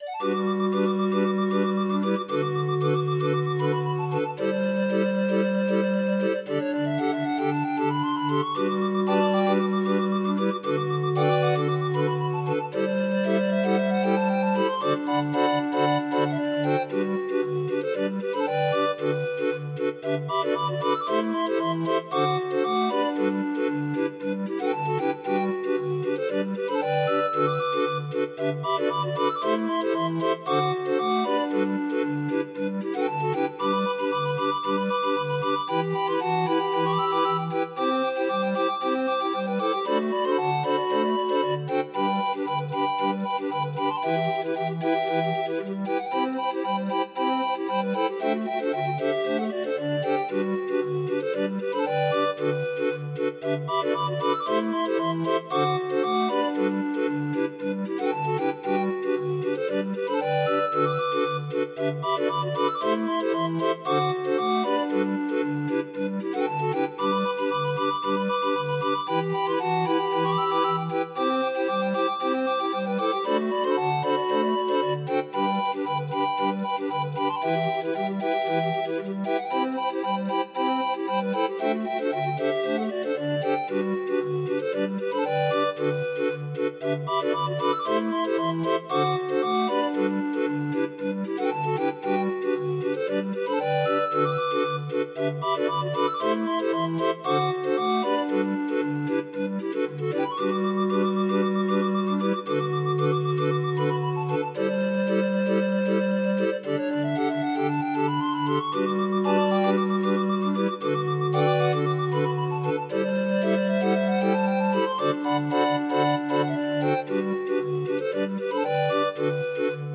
Demo of 25 note MIDI file